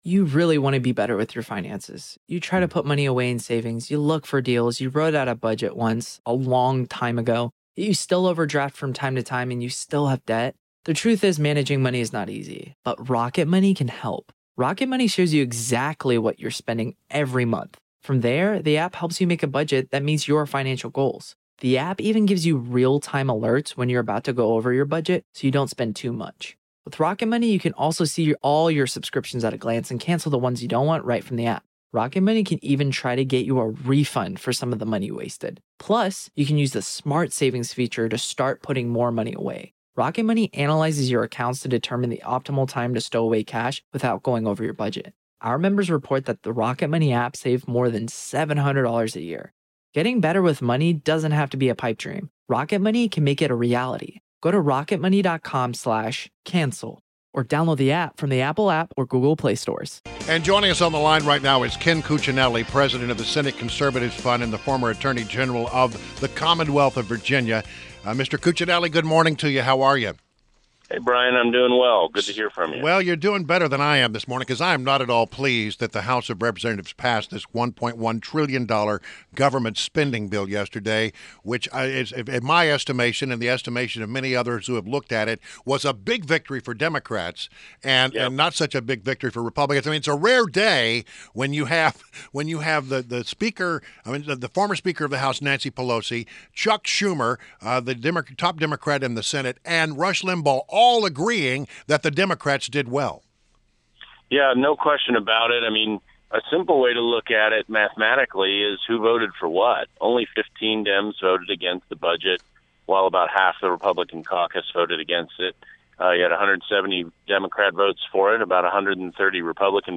INTERVIEW – KEN CUCCINELLI – president of Senate Conservatives Fund and the former Attorney General of Virginia